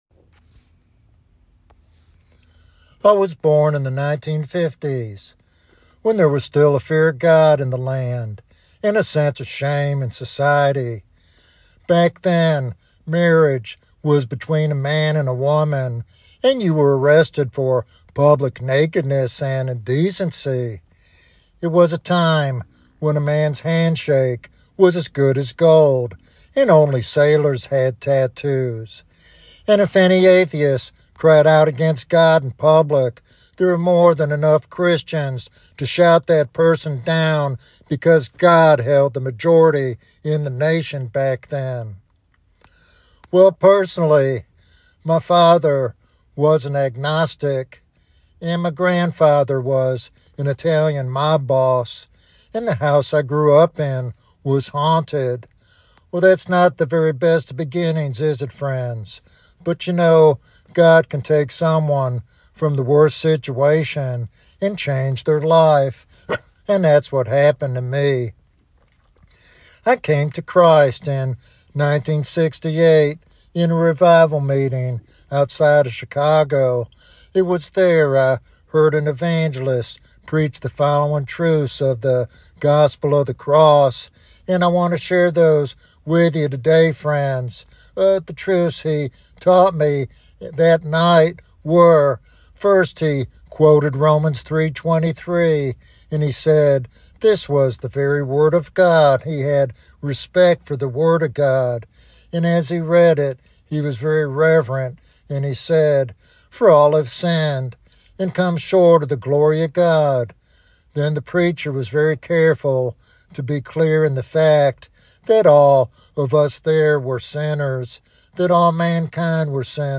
This message is a stirring reminder of Christ’s sacrifice and the hope found only in Him.